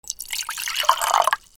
水を注ぐ コップからコップへ
『チョロロロ』